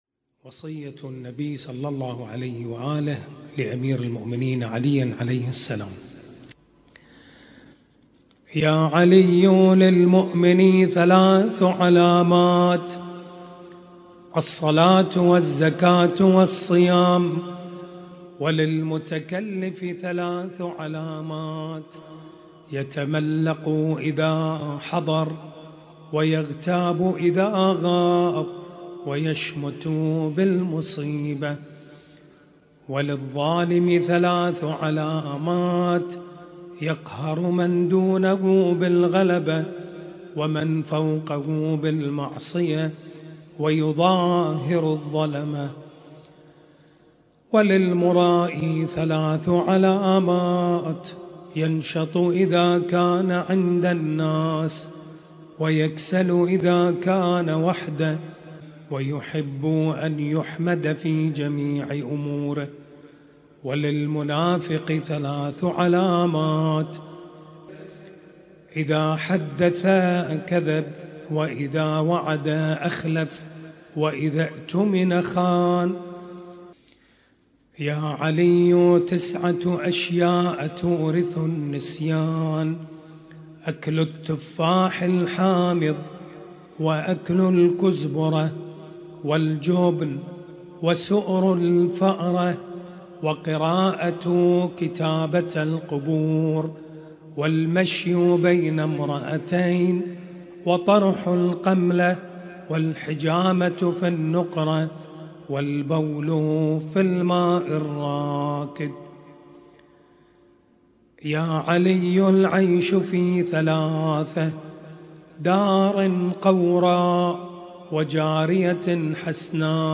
ليلة 13 محرم 1447